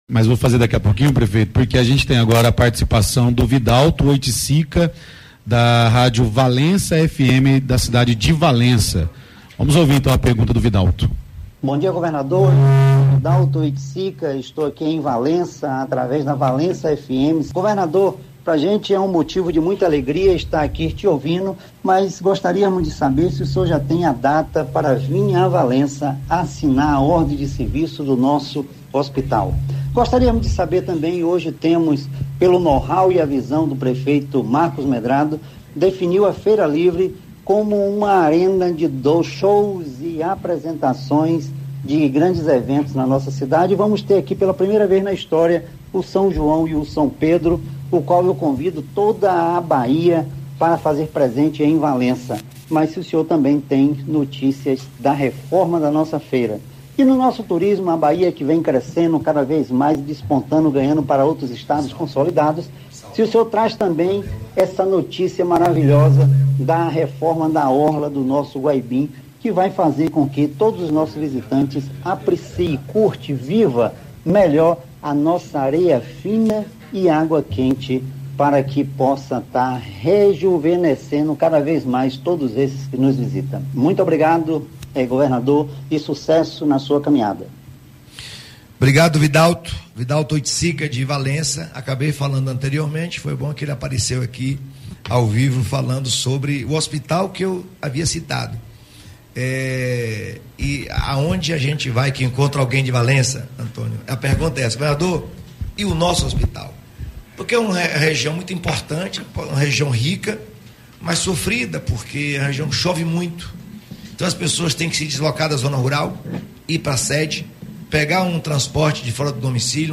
Anúncio foi feito na manhã desta terça-feira (10), durante entrevista concedida a um pool de rádios; Valença FM participou da transmissão e apresentou demandas da cidade ao governador
🔊 Ouça participação da Valença Fm durante entrevista com o governador Jerônimo Rodrigues